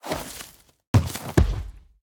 Minecraft Version Minecraft Version 25w18a Latest Release | Latest Snapshot 25w18a / assets / minecraft / sounds / mob / sniffer / digging_stop2.ogg Compare With Compare With Latest Release | Latest Snapshot
digging_stop2.ogg